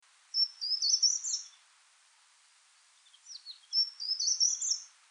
Der Gartenbaumläufer
Gartenbaumlaeufer_audio.mp3